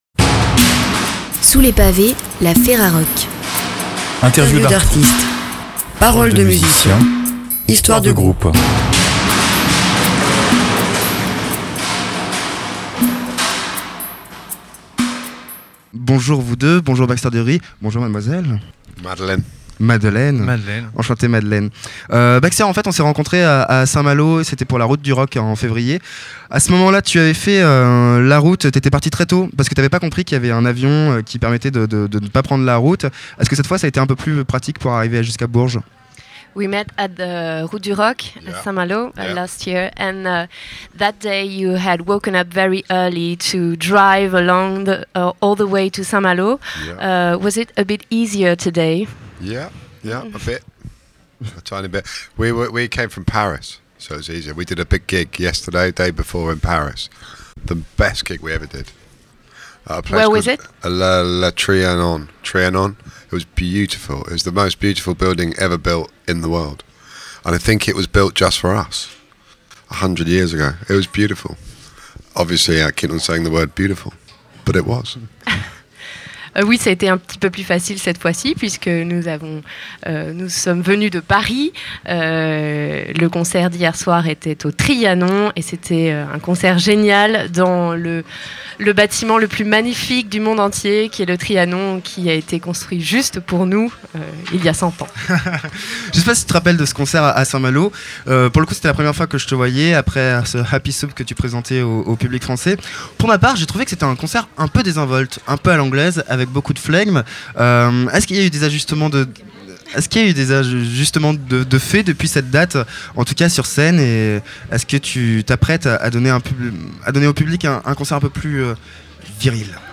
Vous êtes l'artiste ou le groupe interviewé et vous souhaitez le retrait de cet interview ? Cliquez ici.